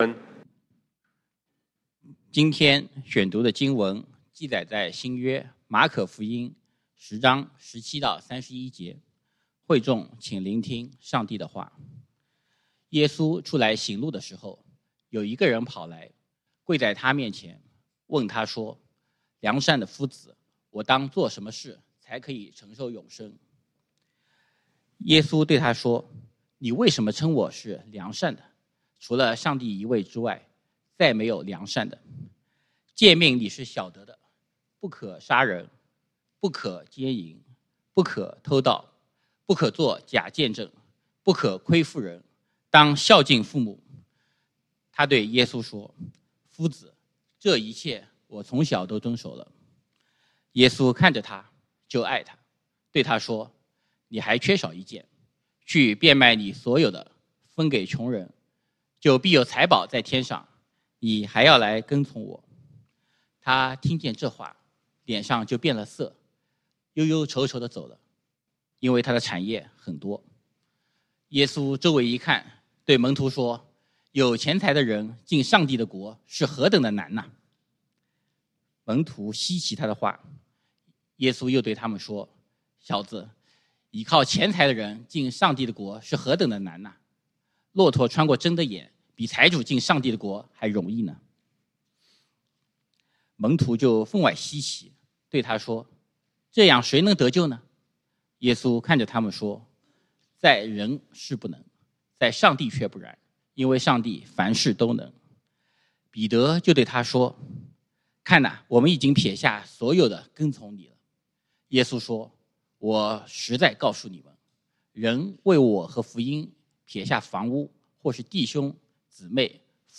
10/20/2024 講道經文：馬可福音 Mark 10:17-31 本週箴言：馬太福音 Matthew 6:24 耶穌說